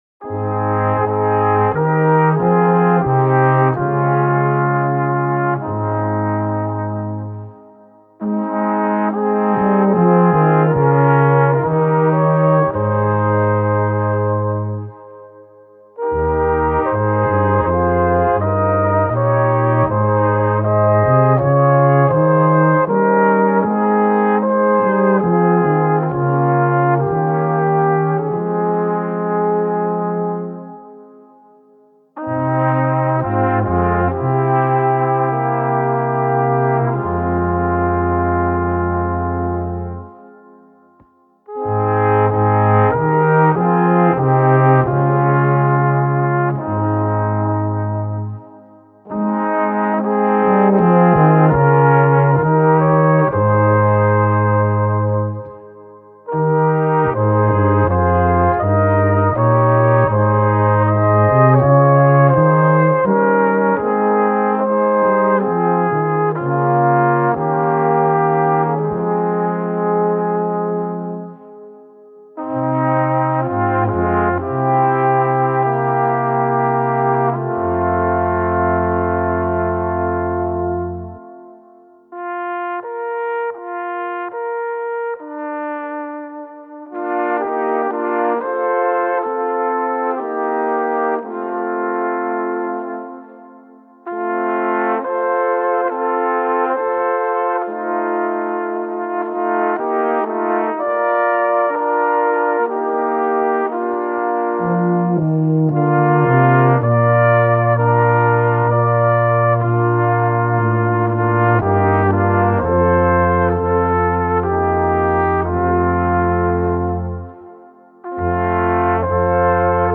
Musikalische Harmonie in vier Stimmen
aus Ramsau im Zillertal (Tuba)
aus Ried im Zillertal (Posaune)
(Flügelhorn)